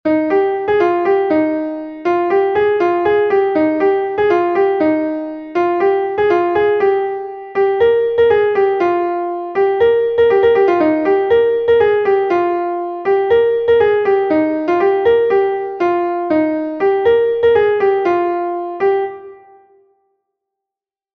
Ton Bale Pleuigner est un Bale de Bretagne